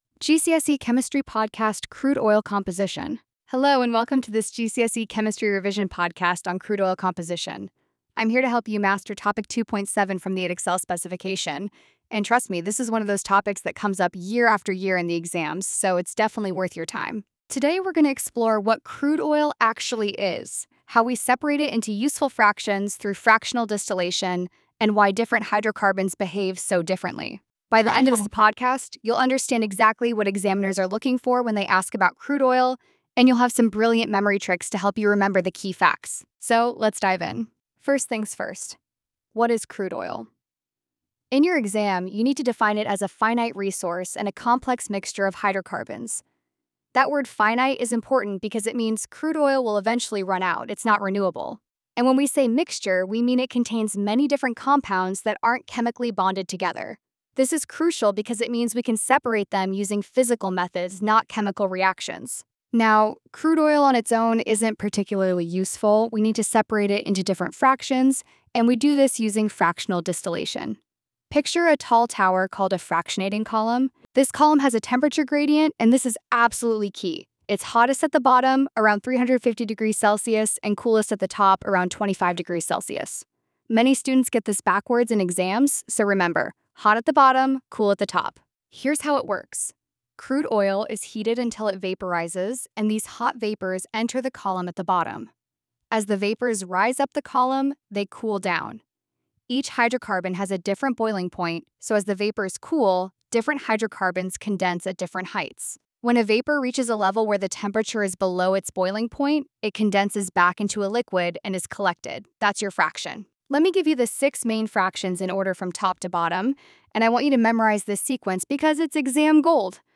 Speaker: Female educator (warm, engaging, confident tone)